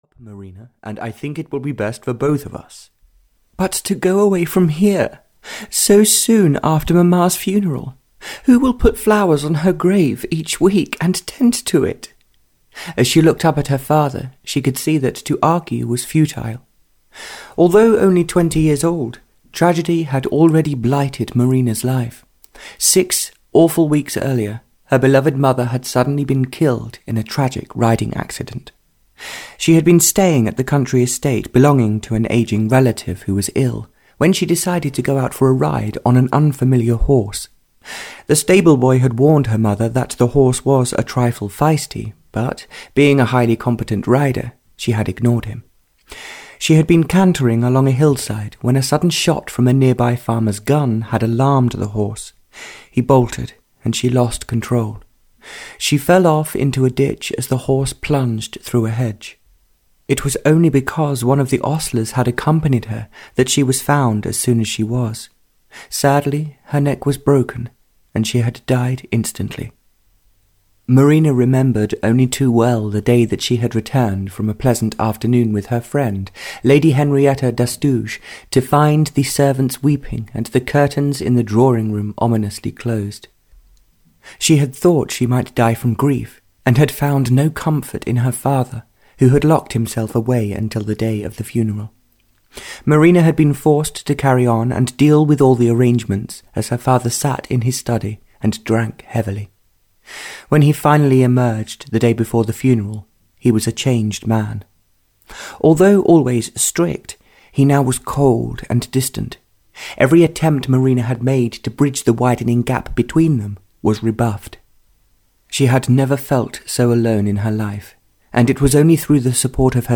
Seeking Love (Barbara Cartland’s Pink Collection 36) (EN) audiokniha
Ukázka z knihy